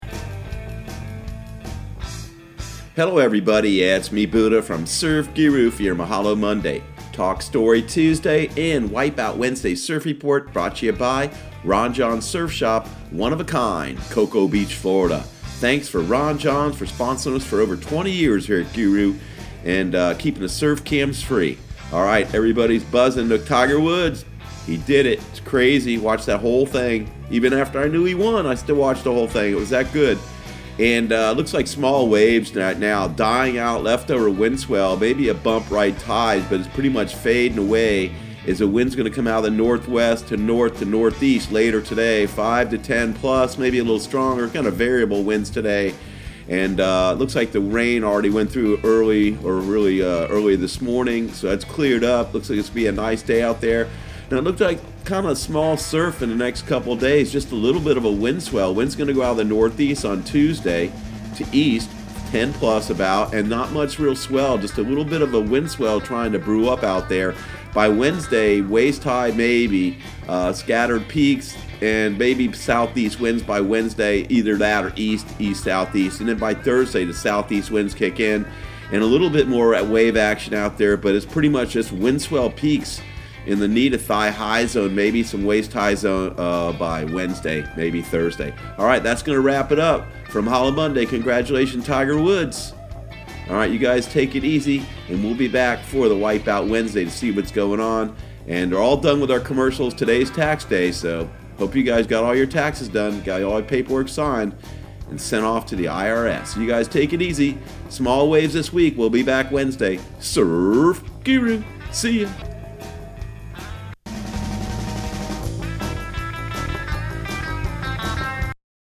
Surf Guru Surf Report and Forecast 04/15/2019 Audio surf report and surf forecast on April 15 for Central Florida and the Southeast.